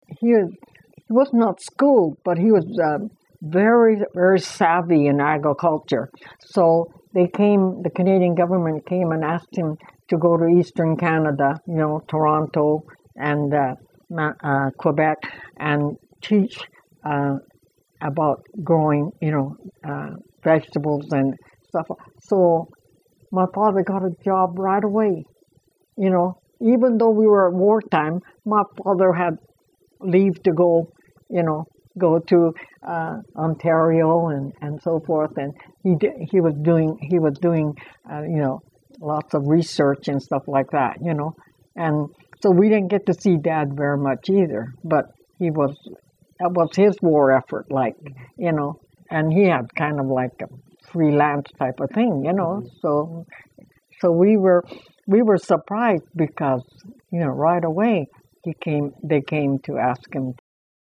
This was one of the longest interviews I've conducted so far.